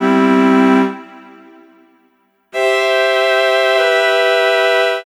Track 13 - Strings 01.wav